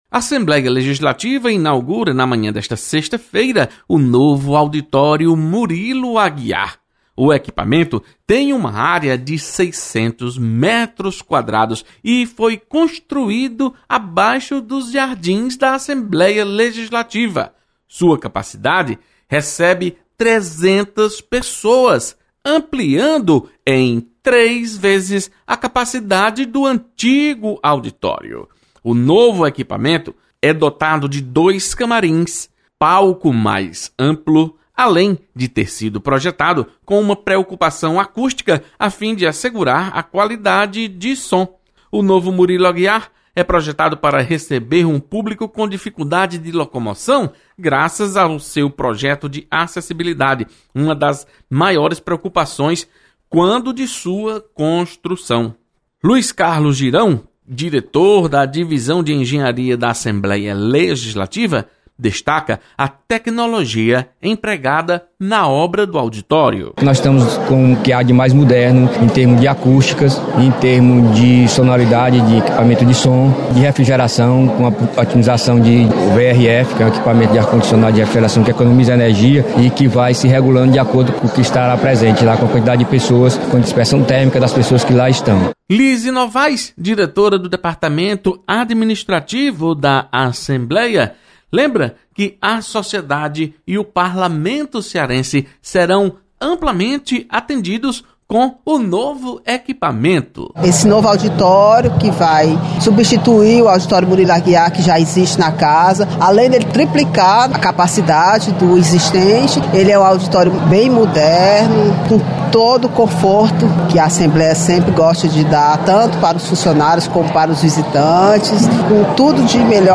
Assembleia apresenta novo auditório Murilo Aguiar. Repórter